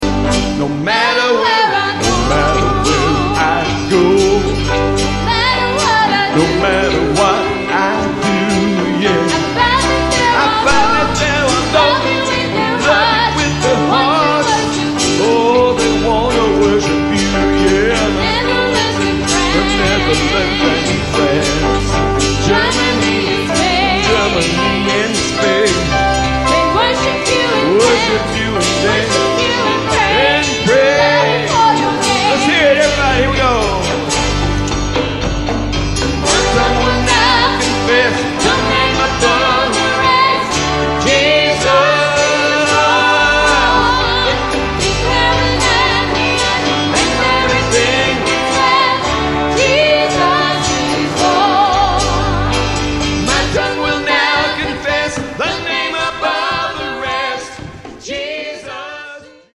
"Live Worship"     $14.95     Buy Now!